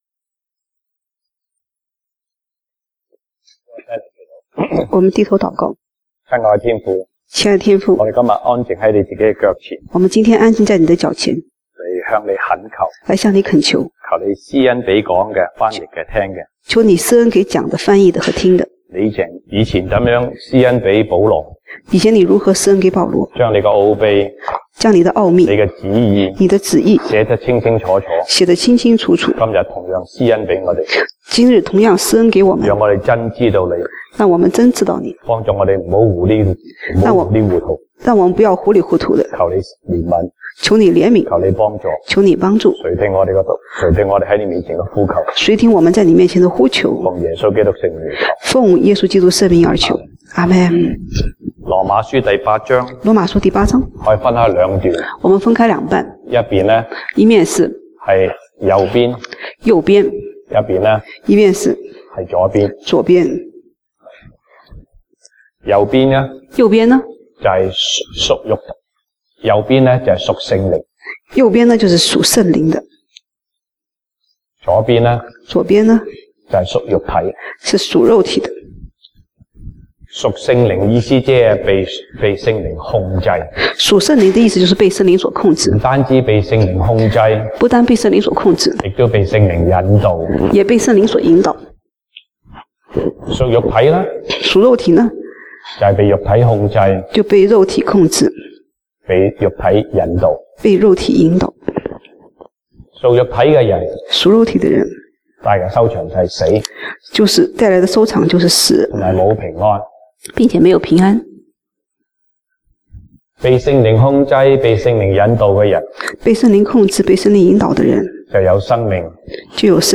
西堂證道(粵語/國語) Sunday Service Chinese: 高山的經歷